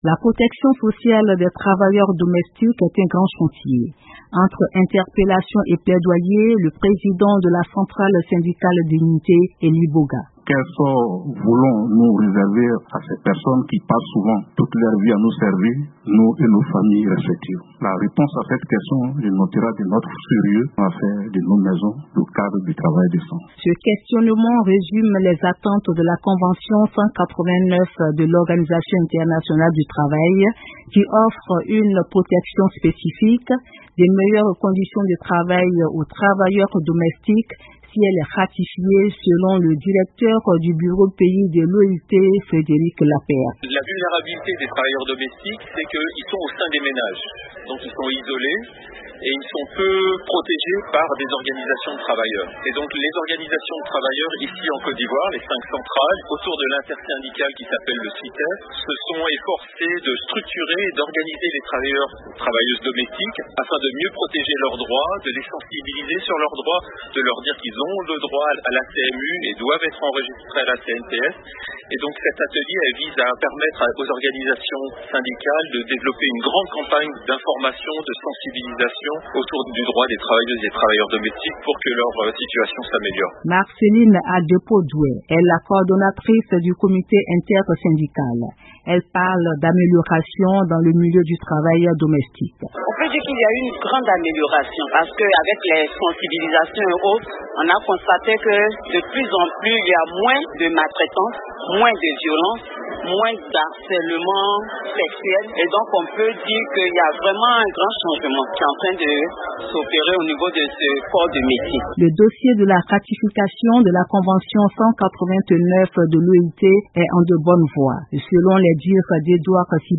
En Côte d’Ivoire, des structures syndicales ont organisé un atelier visant à mener des campagnes de sensibilisation sur la formalisation des droits des travailleurs domestiques. Des détails dans ce reportage